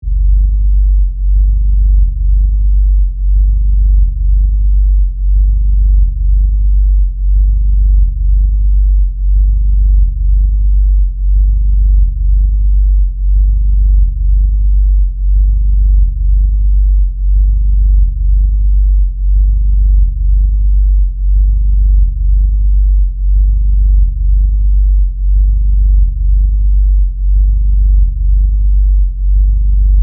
Звуки басов
Глубокий бас с эффектом снижения звука